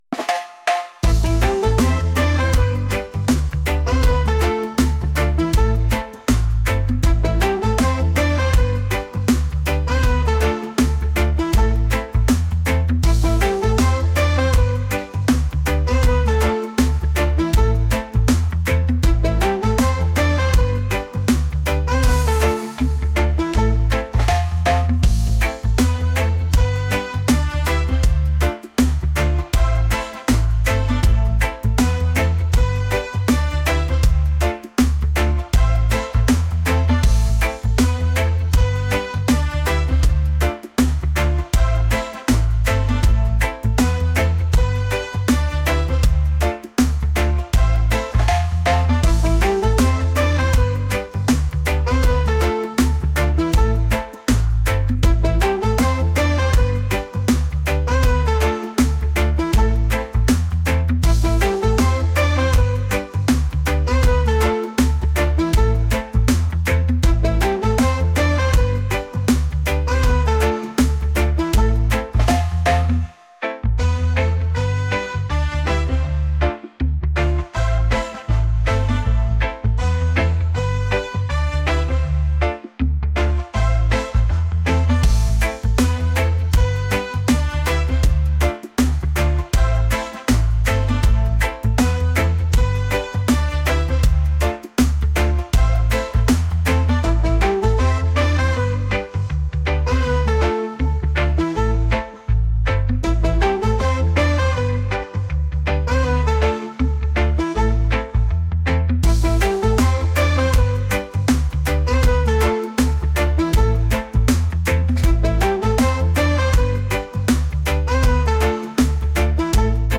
pop | reggae | soul & rnb